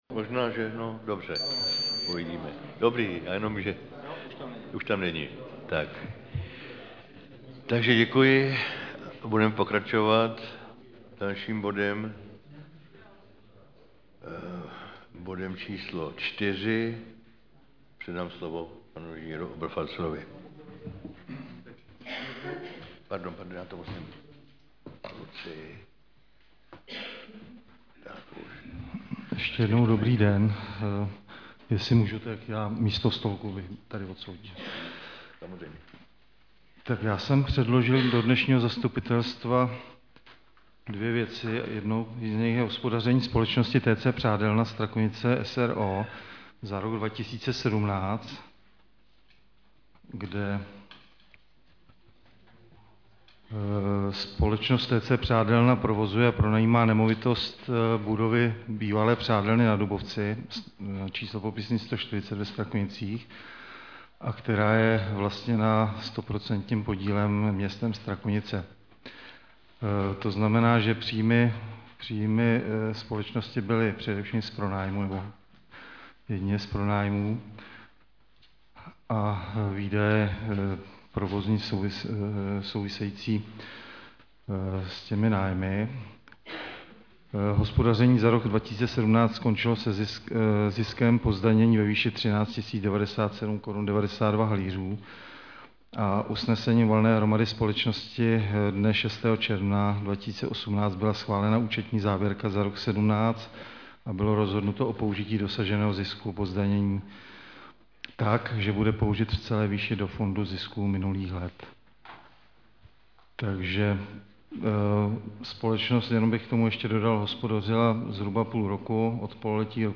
Záznam jednání